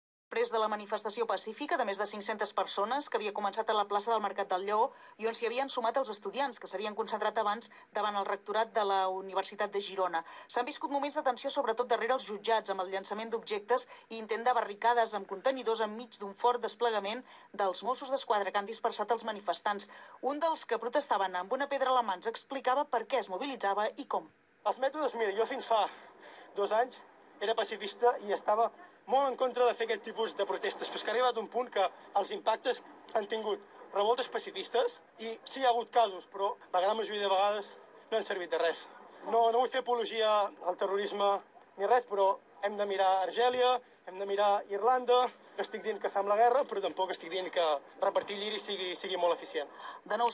En el boletín informativo de la noche de esta emisora el jueves se conectó con Gerona para que una de sus corresponsales hiciera la crónica de los disturbios que se produjeron en esta ciudad.
A continuación entrevistó a uno de los manifestantes.